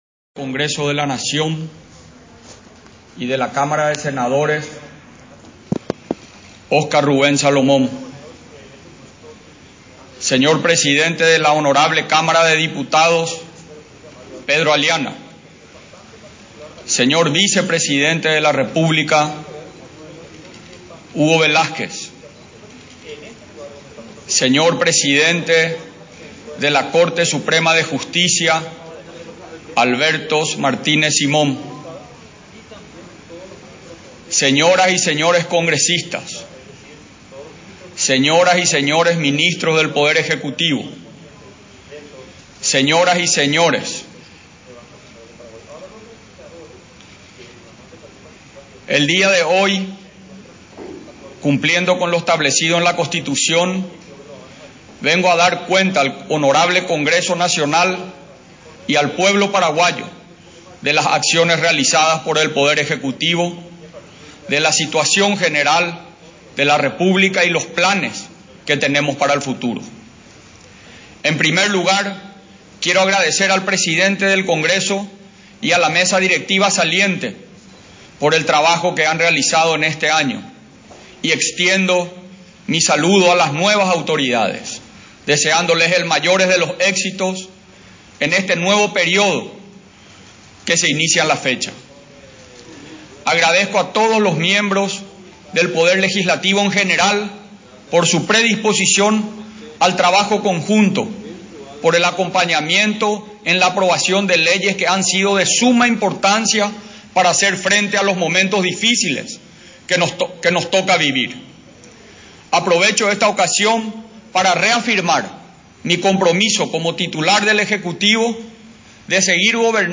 El presidente de la República, Mario Abdo Benítez, presentó hoy miércoles su segundo informe de gestión anual al Congreso Nacional, en el que destacó mayormente las labores de asistencia en la lucha contra la pandemia del coronavirus.